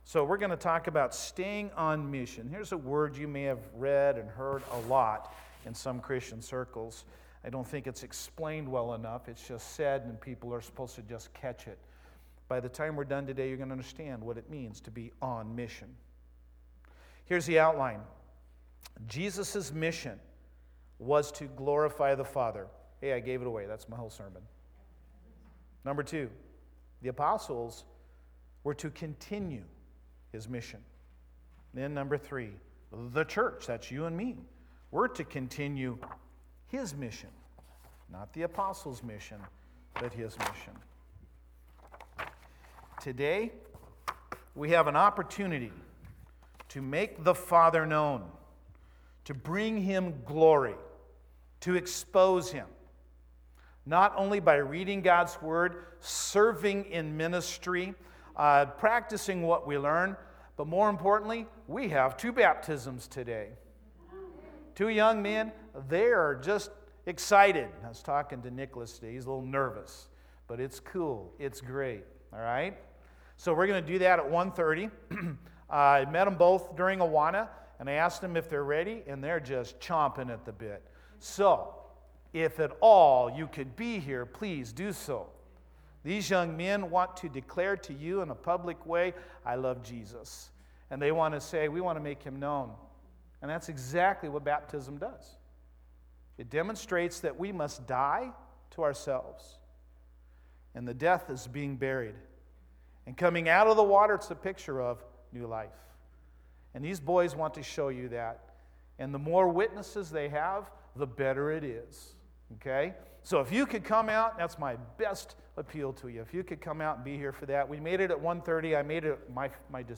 1-12-20-Sermon.mp3